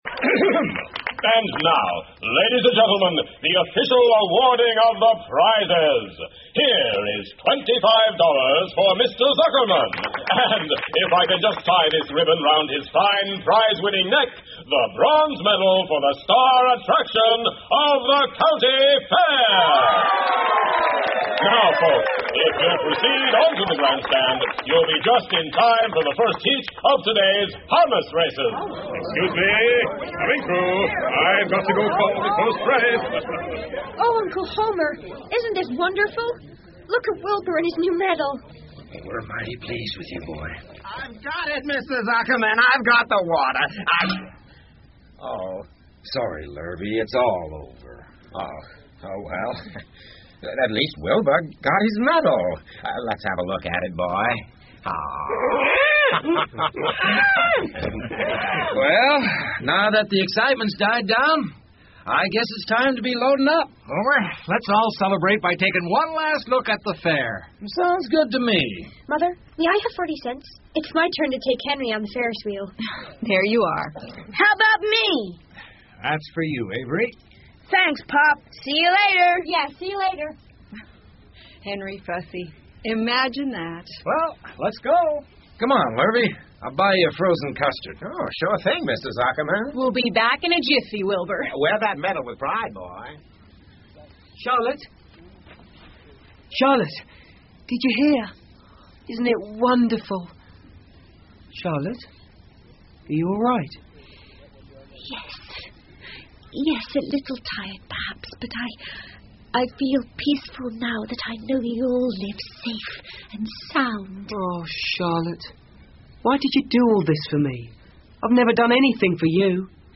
夏洛的网 Charlottes Web 儿童广播剧 16 听力文件下载—在线英语听力室